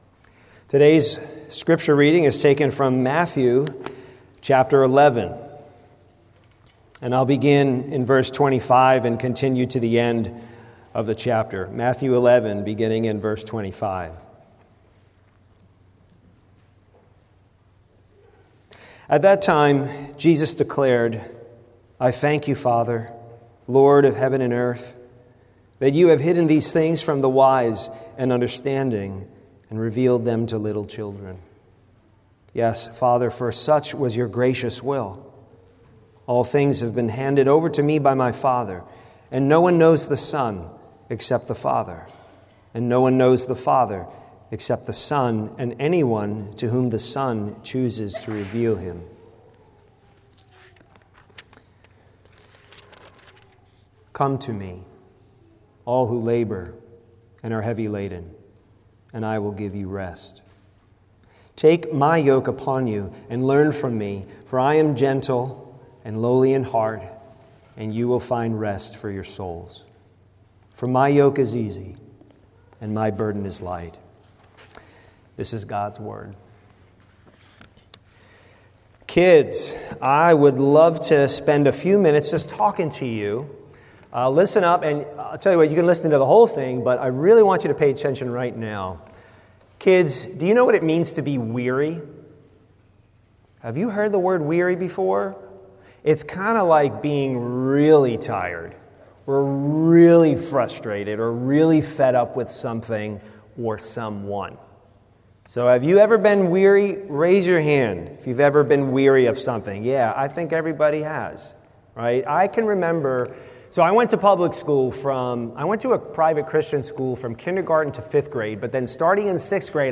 From Series: "Guest Sermons"